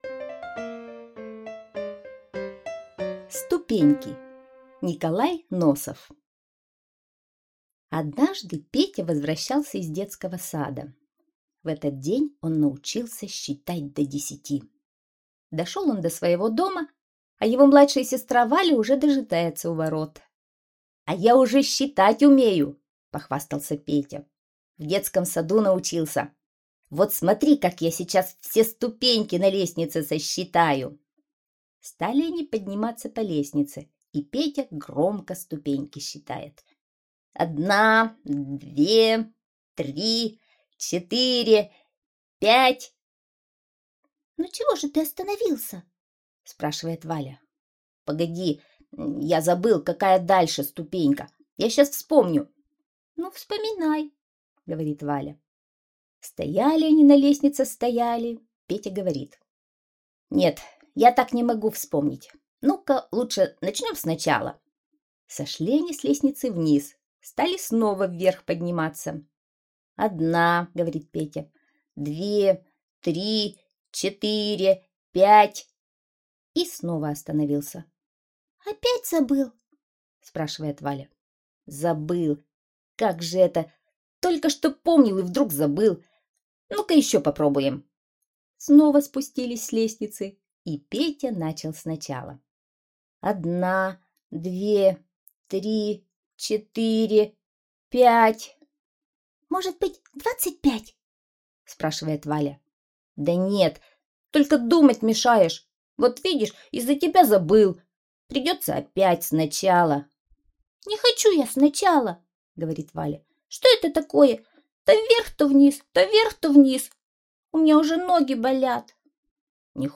Ступеньки - аудио рассказ Носова Н.Н. Петя научился в садике считать до десяти и решил показать сестренке как он считает ступеньки...